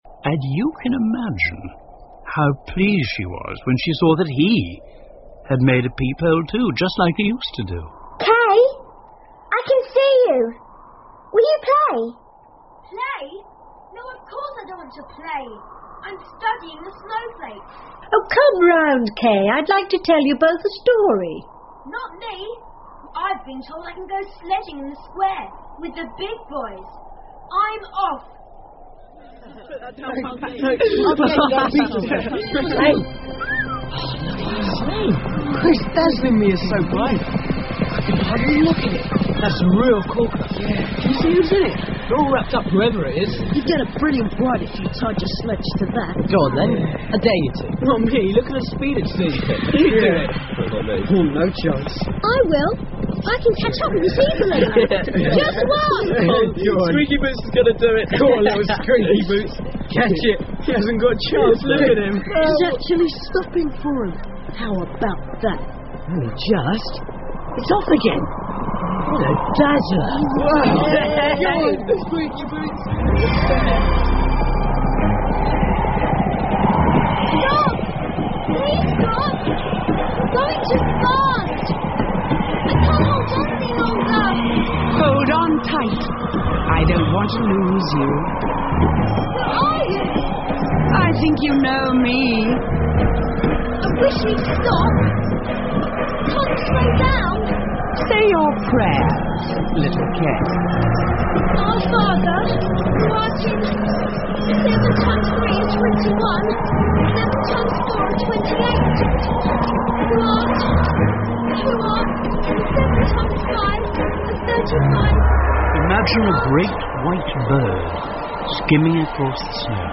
白雪皇后（冰雪女王）The Snow Queen 儿童广播剧 3 听力文件下载—在线英语听力室